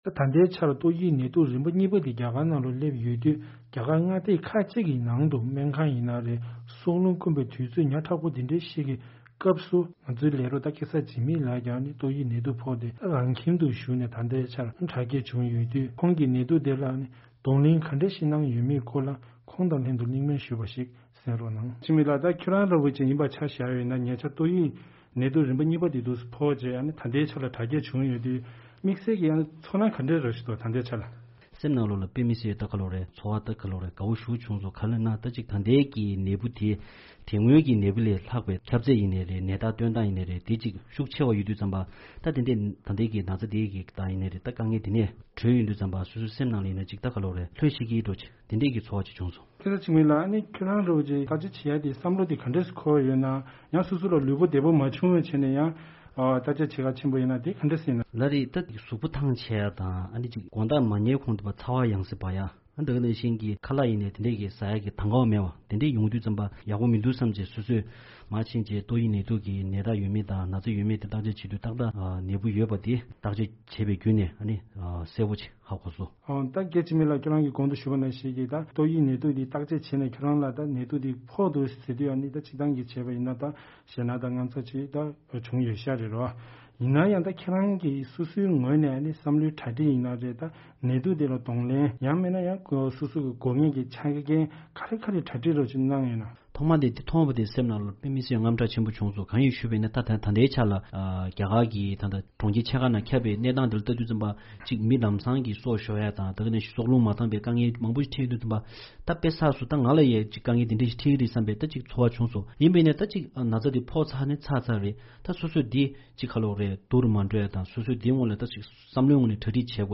བཅར་འདྲི་ཞུས་ཡོད་པའི་སྐོར།